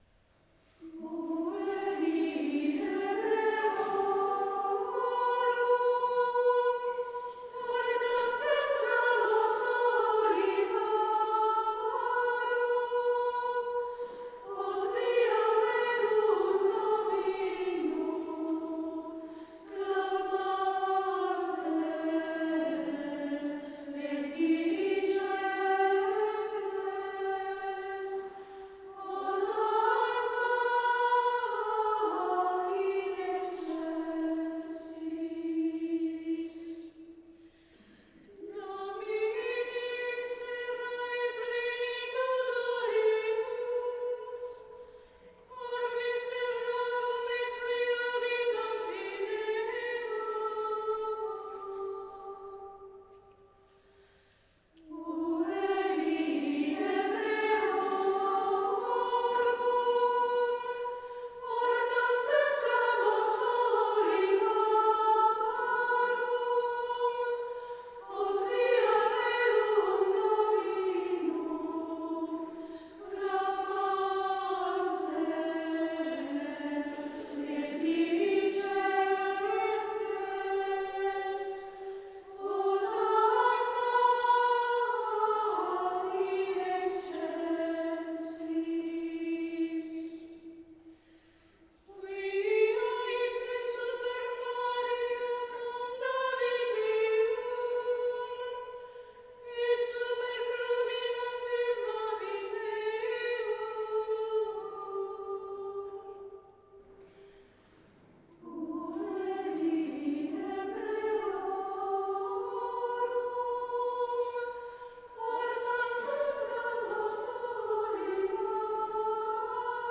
Elevazione Musicale Santa Pasqua 2025.
Basilica di S.Alessandro in Colonna, Bergamo
Canto gregoriano